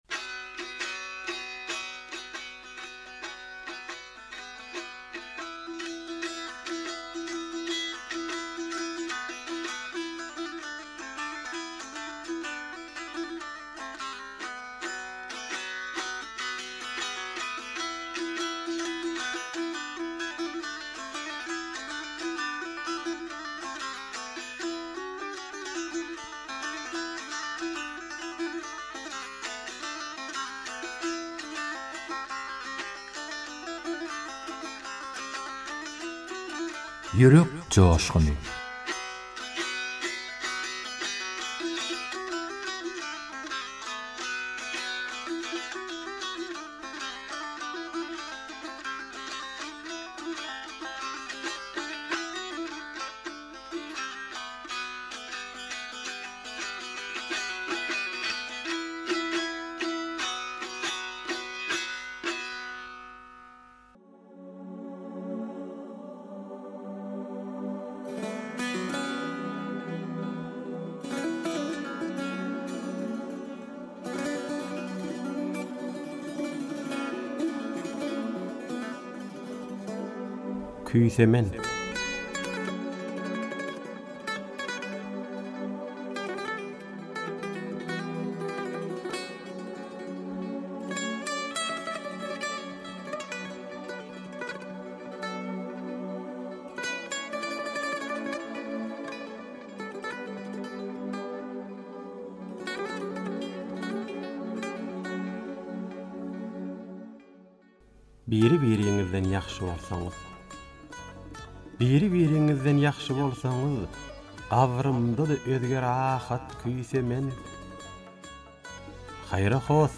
turkmen goşgy owaz aýdym